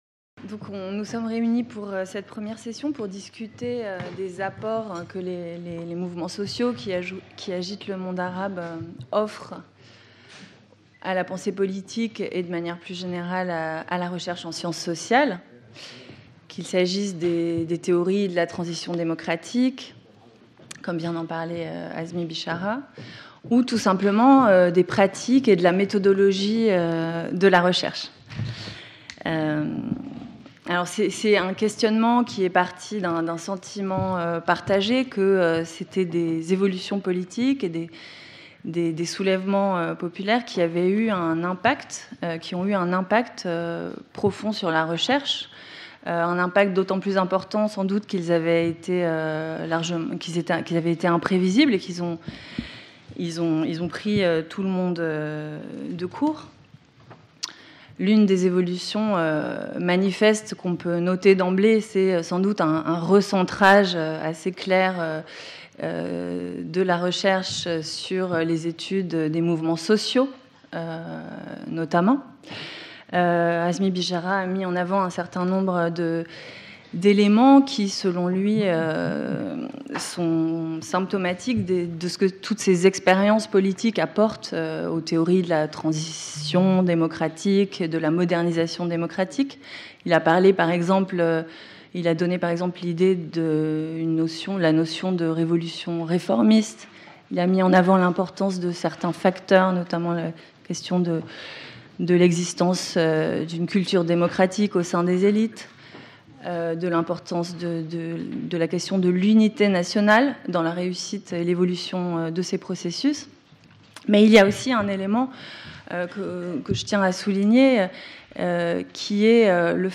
This first round-table examines the production of knowledge on the collective dynamics at work in the Arab world, from the "springs" of 2011 to the uprisings in Algeria and Sudan. How have the Arab springs impacted research (sociology, political science, history?)?